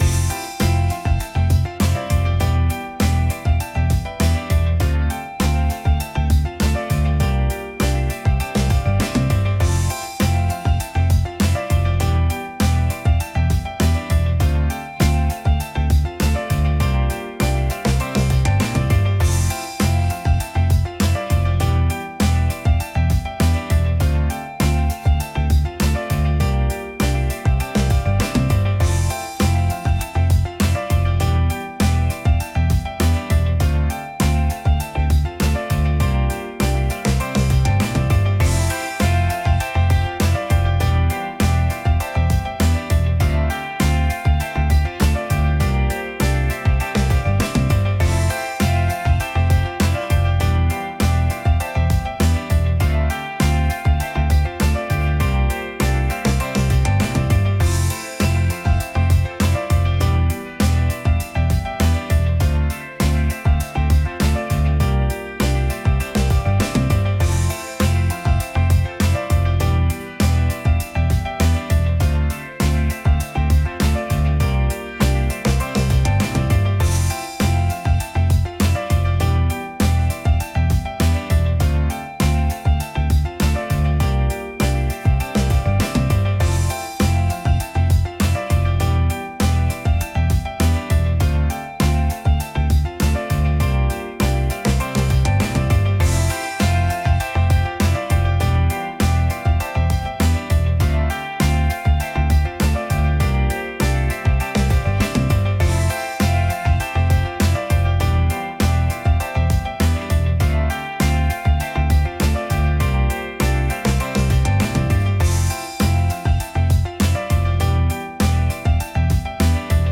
明るい